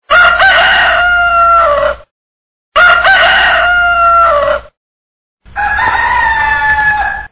chicchirichì scandisce i tempi e la vita di tutti gli animali in maniera molto rigorosa.
cv4_gallo.mp3